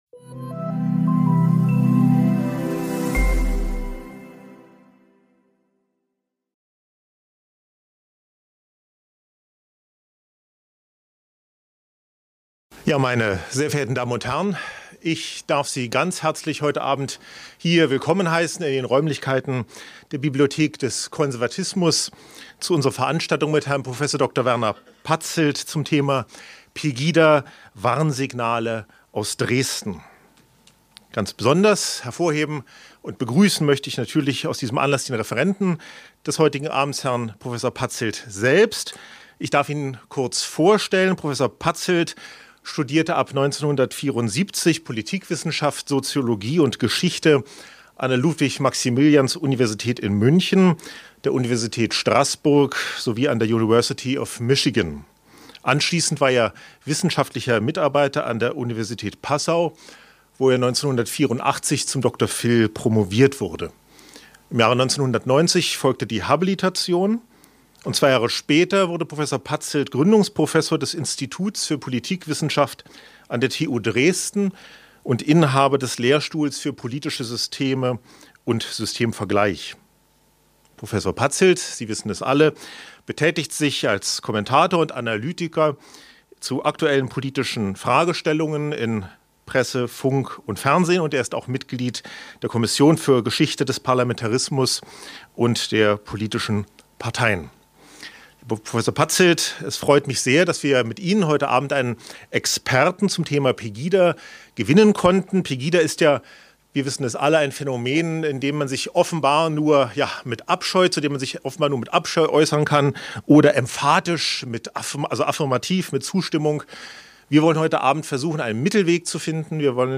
Mit diesen Fragen setzte sich der Dresdener Politikwissenschaftler Werner Patzelt am 4. Mai 2016 vor über 130 Zuhörern in der Bibliothek des Konservatismus auseinander.